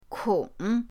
kong3.mp3